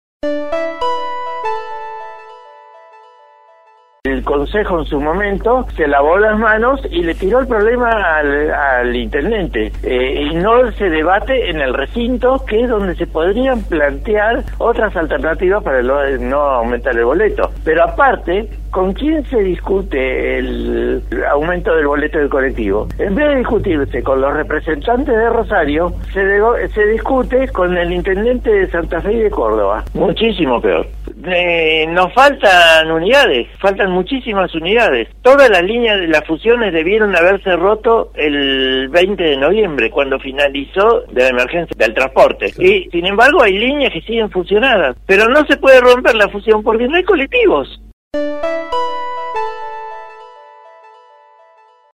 Lo afirmó en diálogo con el programa La barra de Casal, por LT 3